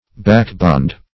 Backbond \Back"bond`\ (b[a^]k"b[o^]nd`), n. [Back, adv. + bond.]